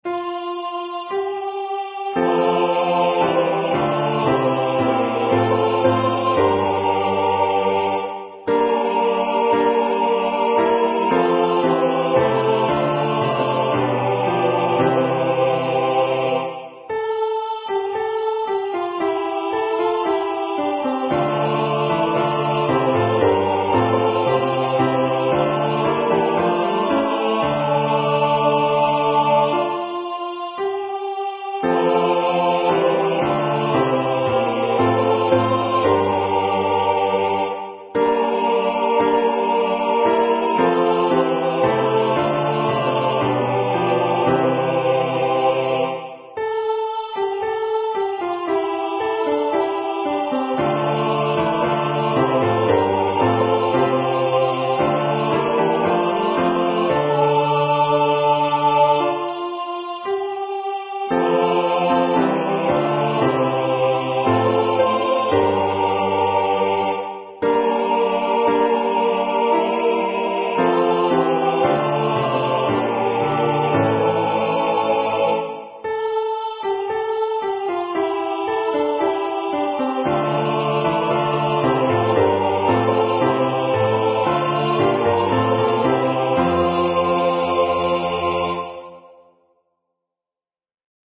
Number of voices: 4vv   Voicing: SATB
Genre: SacredMass